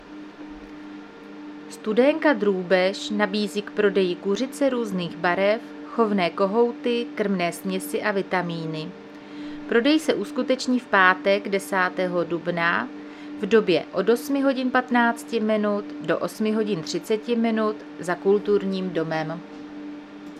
Záznam hlášení místního rozhlasu 9.4.2026
Zařazení: Rozhlas